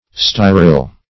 Search Result for " styryl" : The Collaborative International Dictionary of English v.0.48: Styryl \Sty"ryl\, n. [Styrax + -yl.]